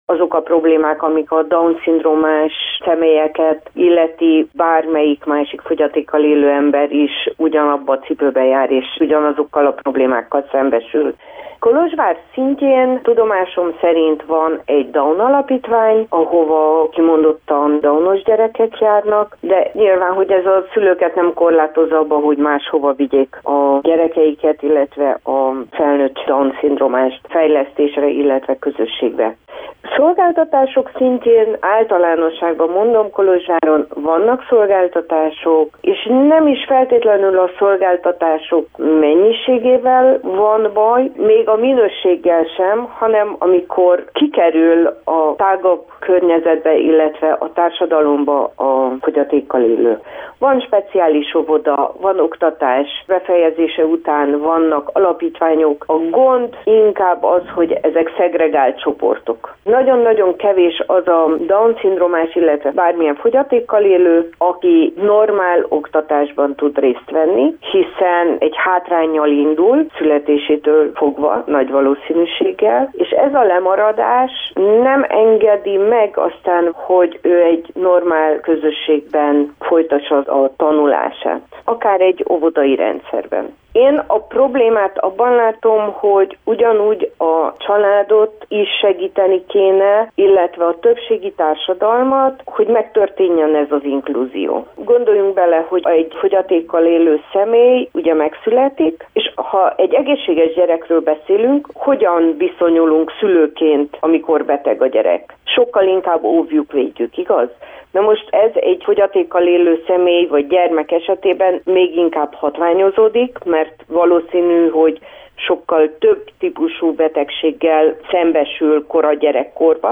A fogyatékkal élők kolozsvári ellátórendszeréről, és arról, hogy hogyan viszonyuljunk a Down-szindrómás embertársainkhoz, a Máltai Szeretetszolgálat munkatársával beszélgettünk.